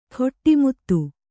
♪ khoṭṭi muttu